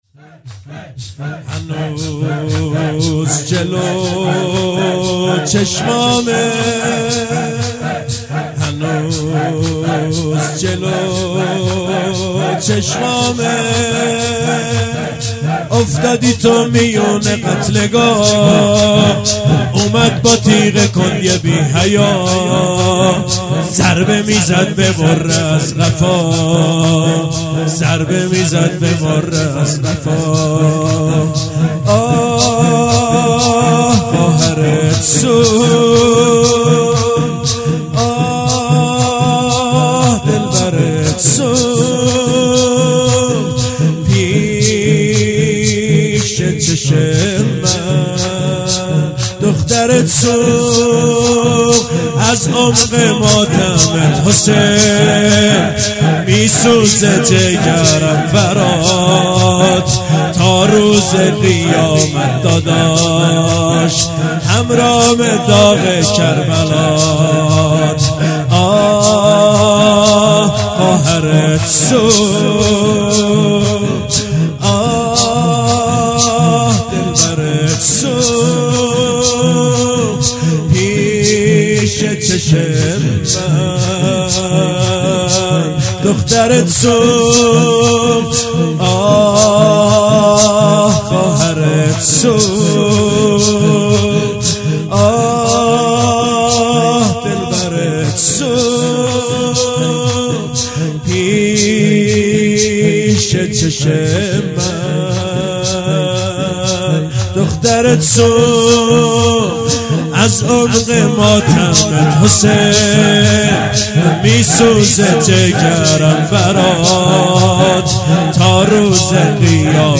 شب دهم محرم - زمینه - هنوز جلو چشمامه افتادی تو میون قتلگاه اومد با تیغ کند یه بی حیا
محرم 1397